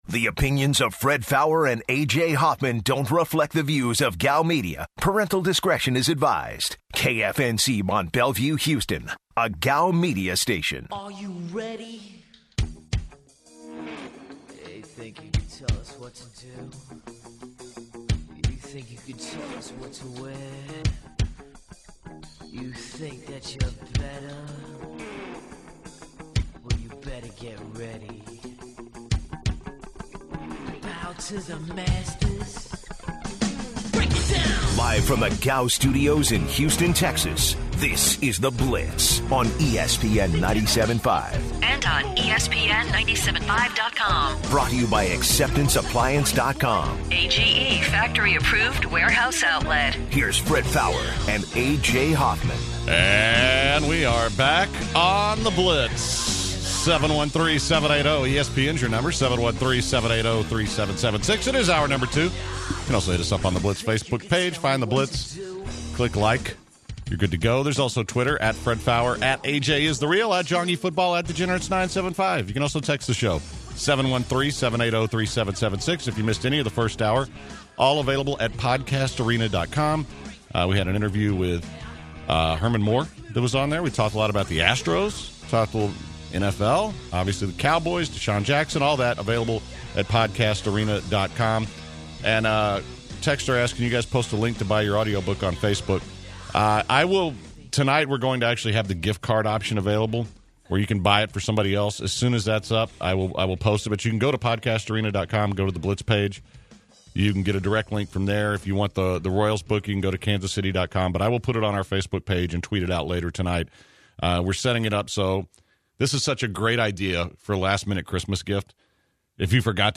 To open the second hour, the guys share their thoughts on their favorite TV shows. Plus, UFC straw weight fighter Paige VanZant joins the show to discuss her upcoming fight and her rise in the UFC.